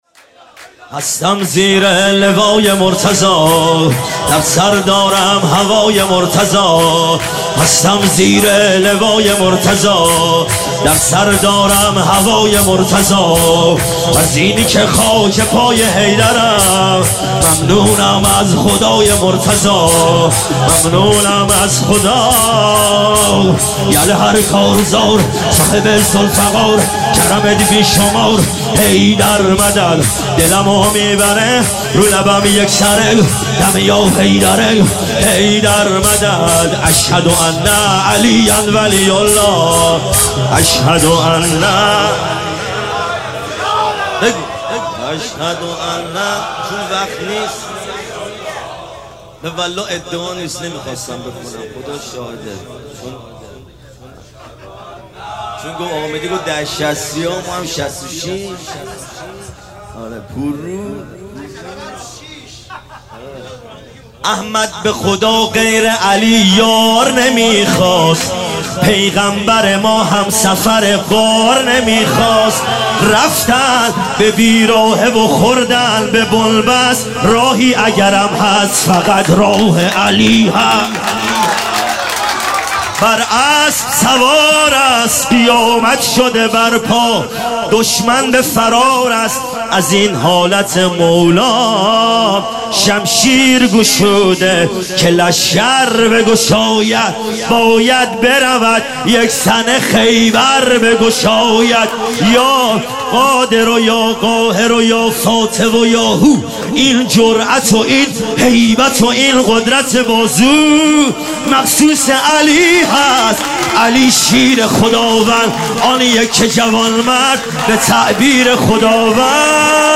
عنوان ولادت سرداران کربلا – شب دوم
سرود-شور